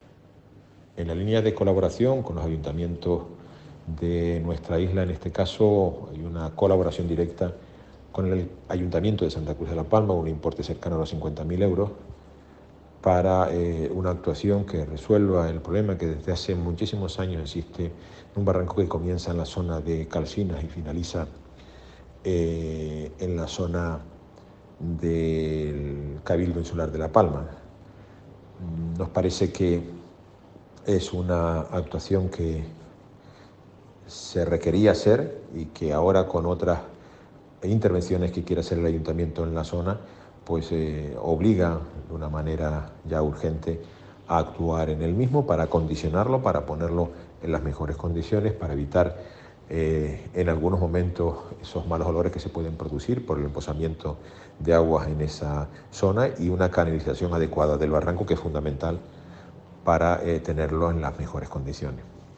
Declaraciones audio Carlos Cabrera (1).mp3